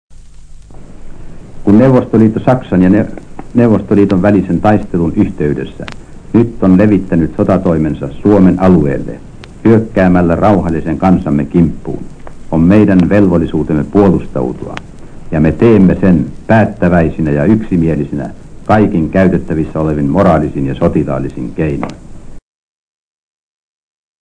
Die Rede wurde von Präsident Ryti am 26.6.1941 unmittelbar nach Ausbruch des Krieges zwischen Finnland und der Sowjetunion gehalten. Er sagt, daß es Finnlands Pflicht sei, sein Territorium zu verteidigen, weil die Sowjetunion angegriffen habe.